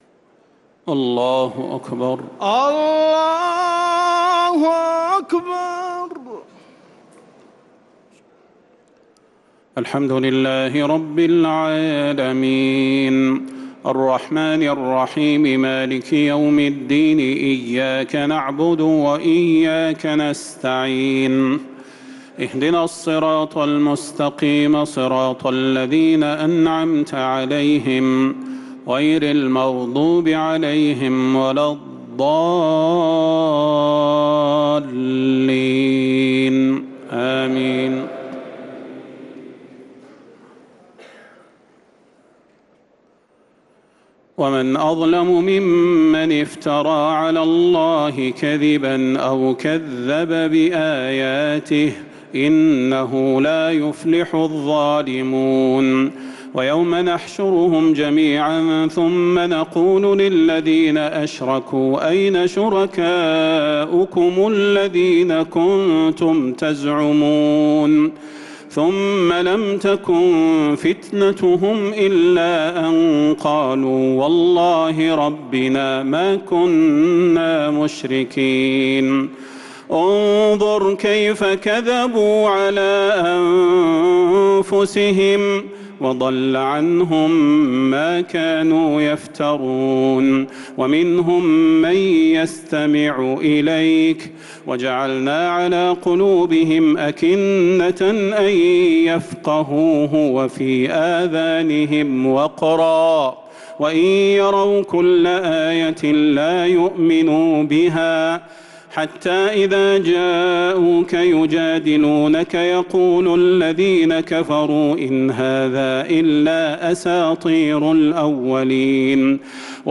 تراويح ليلة 9 رمضان 1446هـ من سورة الأنعام (21-73) | Taraweeh 9th niqht Ramadan 1446H Surat Al-Anaam > تراويح الحرم النبوي عام 1446 🕌 > التراويح - تلاوات الحرمين